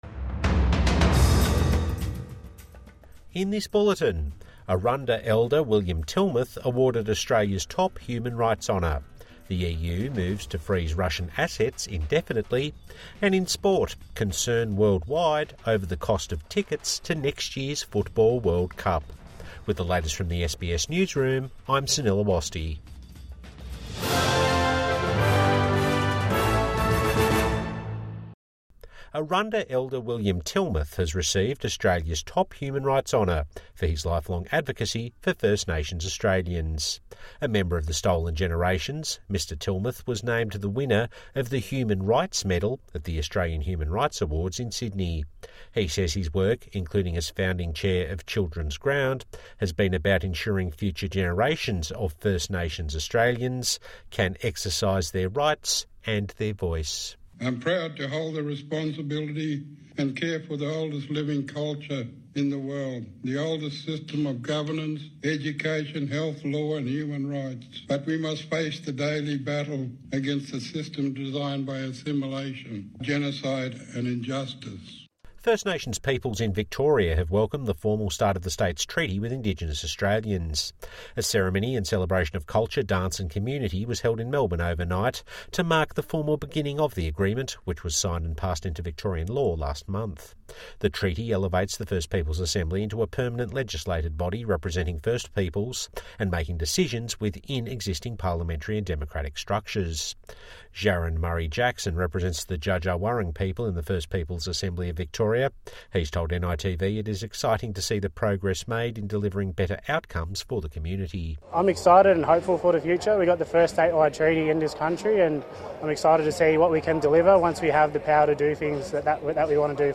Indigenous leader wins Australia's top human rights award | Midday News Bulletin 13 December 2025